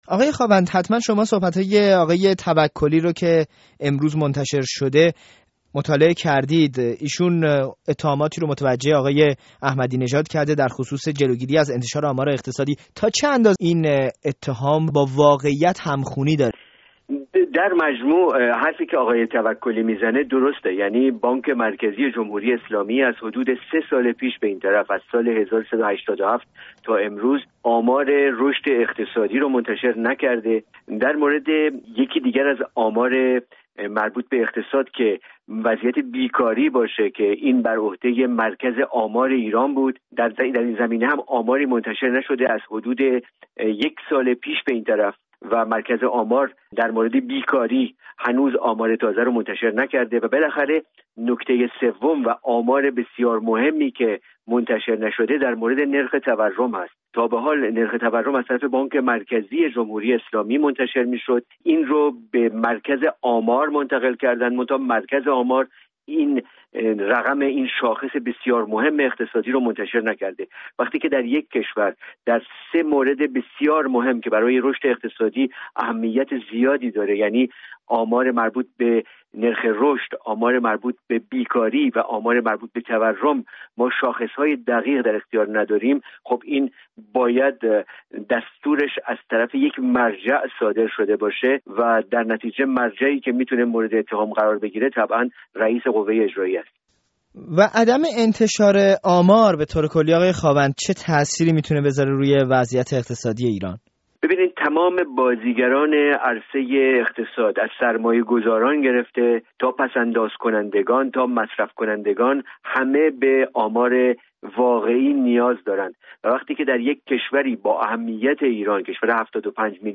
گفت‌وگوی رادیوفردا